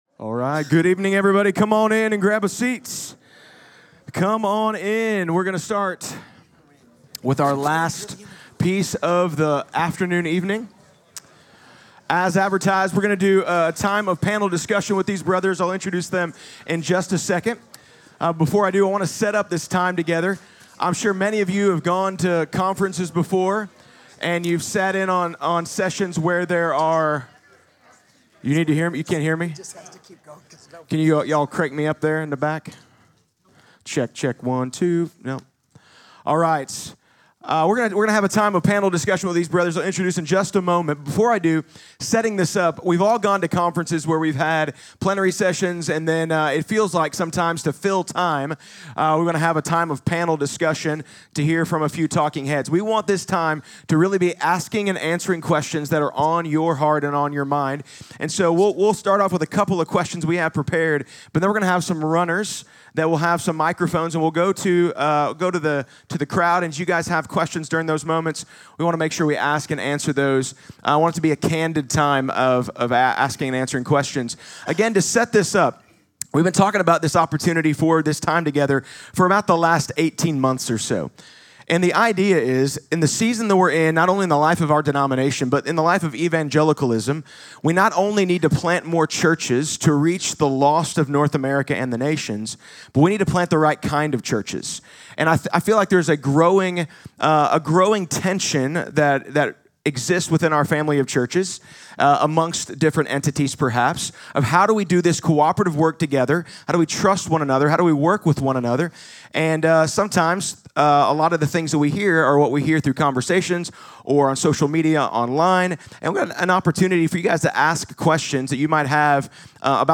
2025 Southern Missions Conference Session 2 Panel Discussion — Southern Equip